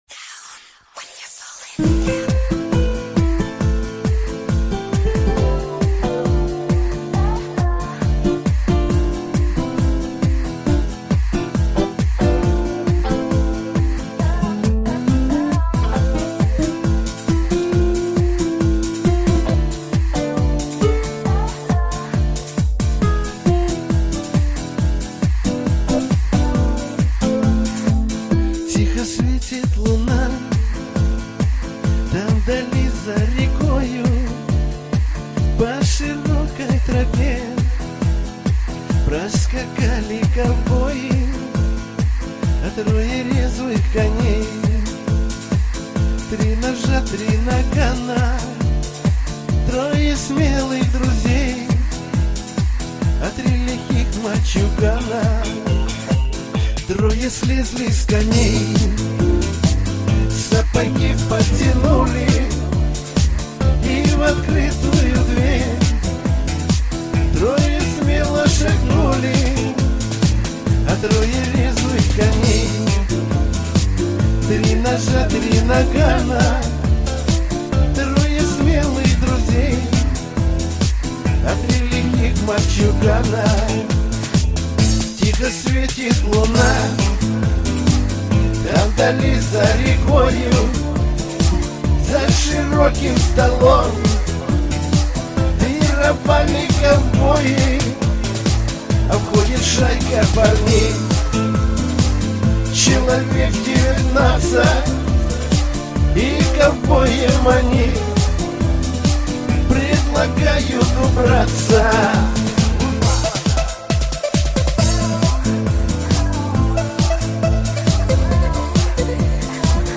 Это была известная в свое время ДВОРОВАЯ песня .